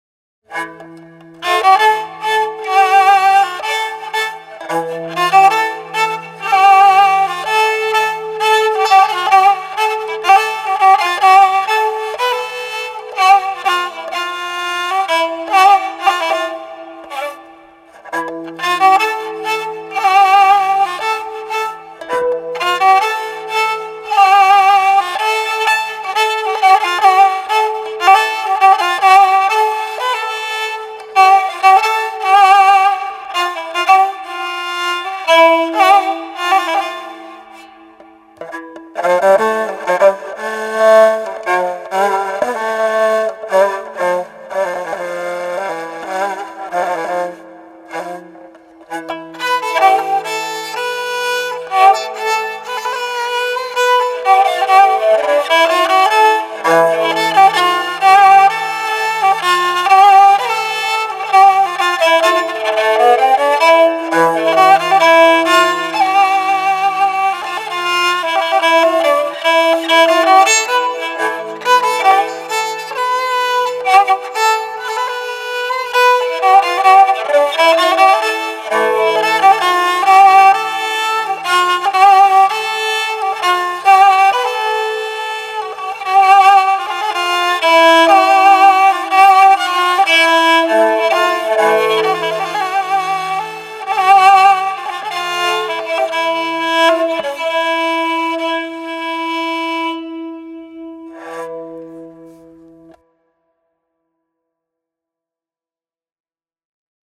در ابوعطا
در قالب تکنوازی